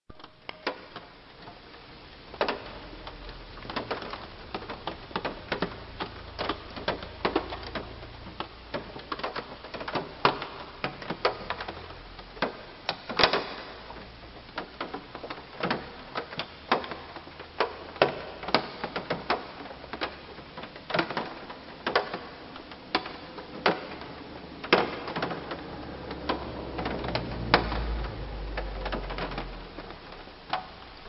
描述：去山里慢跑，下雨了，雨滴很慢而且很猛，因为我停在树下，最后你可以听到一辆车从后面经过的声音
Tag: 雨滴 屋顶